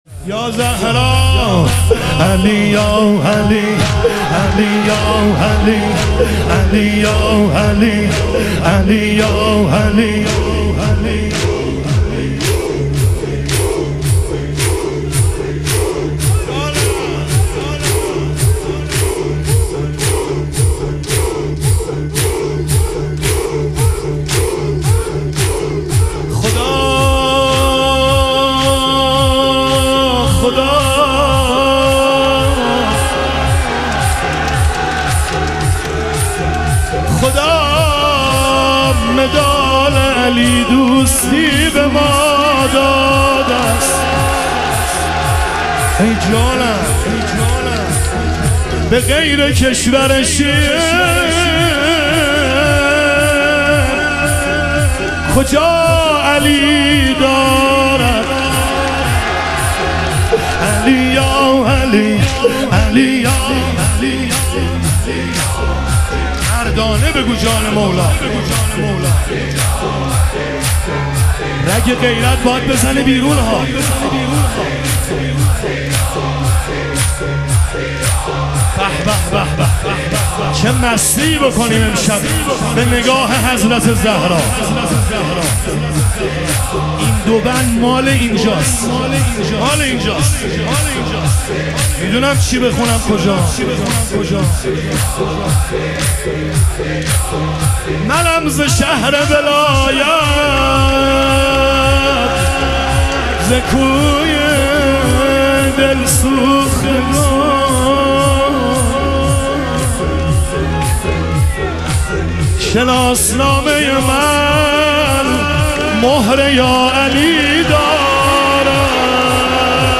شهادت حضرت خدیجه علیها سلام - تک
شب شهادت حضرت خدیجه علیها سلام